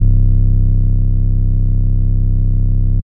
Rnb Trapsoul 808.wav